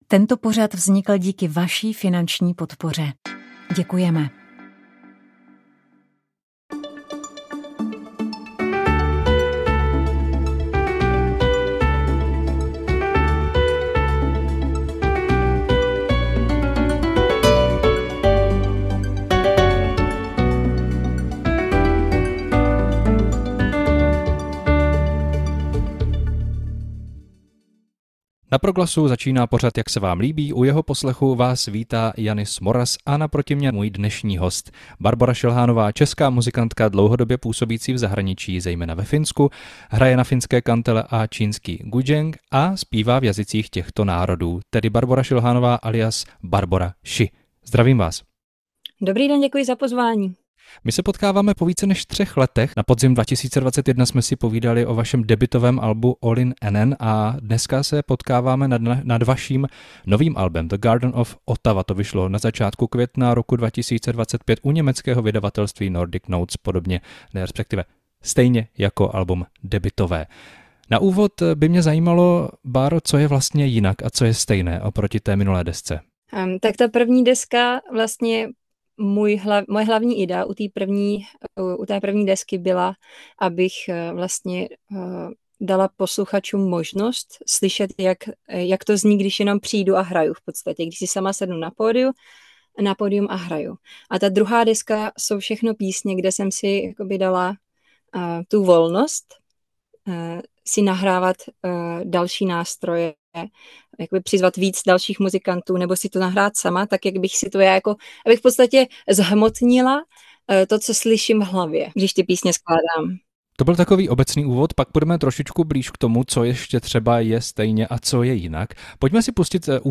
Nové album s názvem Tady to máš vydal na konci roku 2024 zpěvák Tomáš Klus. Rozhovor s ním vám nabídneme v pondělí 31. března 2025 v pořadu Jak se vám líbí.